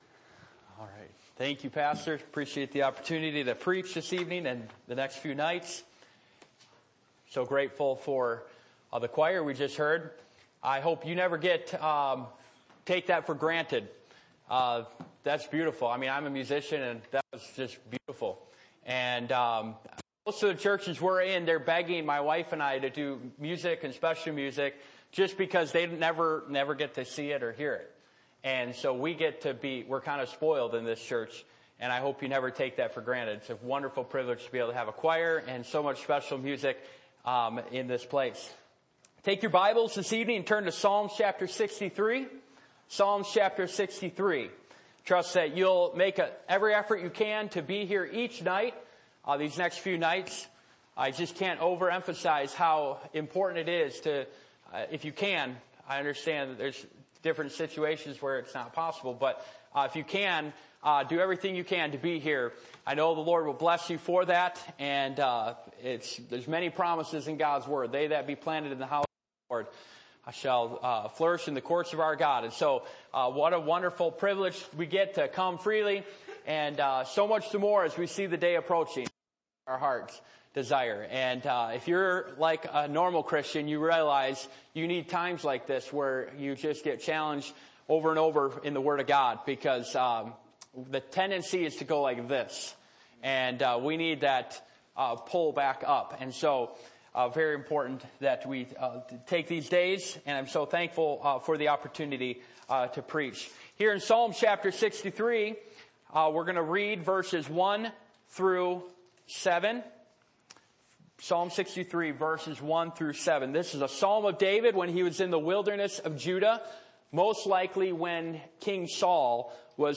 Passage: Psalm 63:1-11 Service Type: Sunday Evening